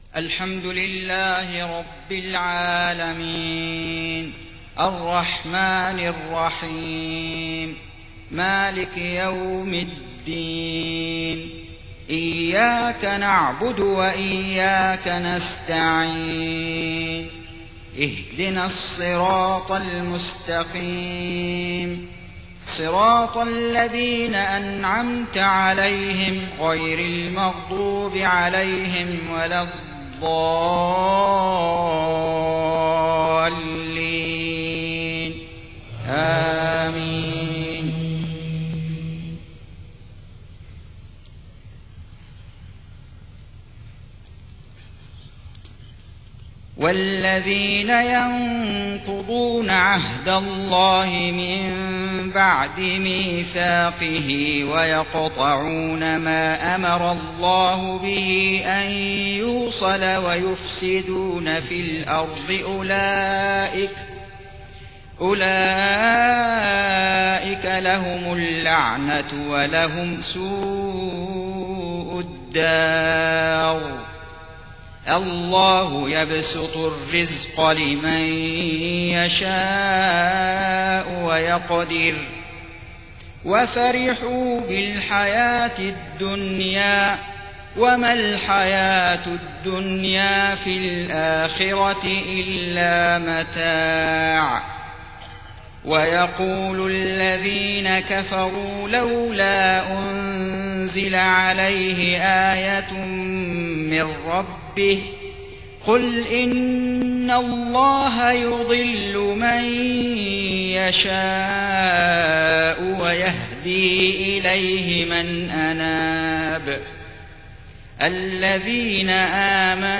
صلاة العشاء 21 محرم 1430هـ من سورة الرعد 25-34 > 1430 🕋 > الفروض - تلاوات الحرمين